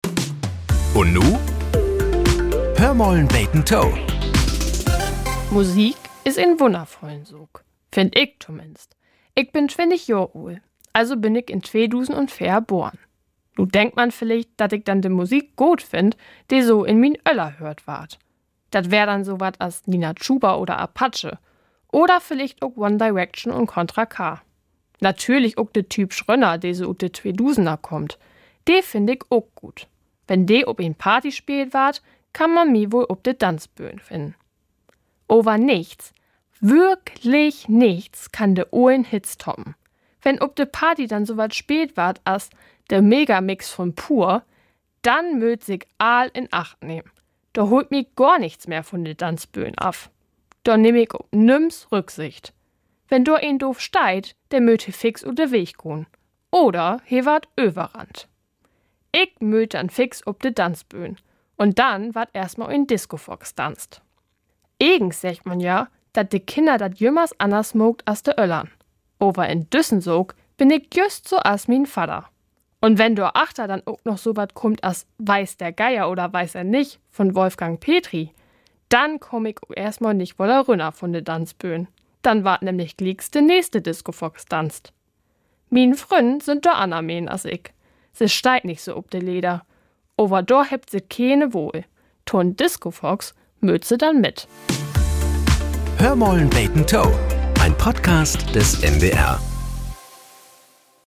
Nachrichten - 08.01.2025